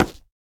nether_wood_button.ogg